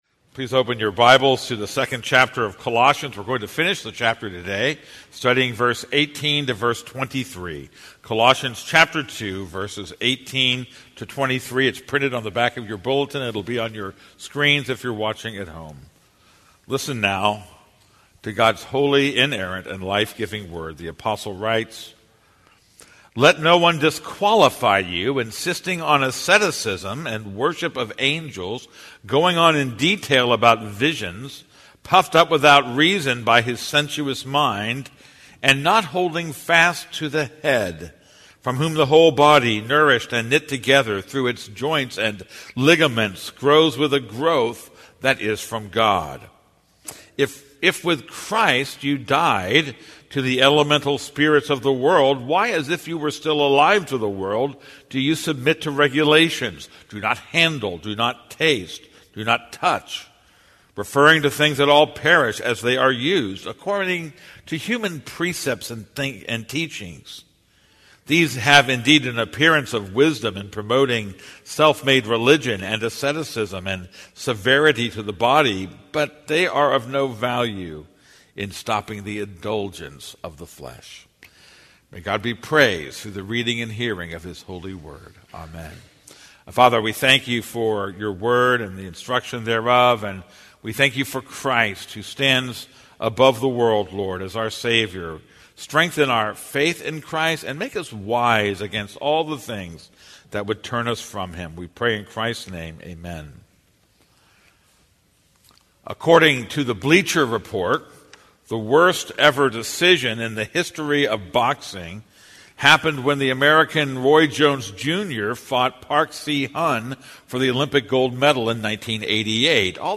This is a sermon on Colossians 2:18-23.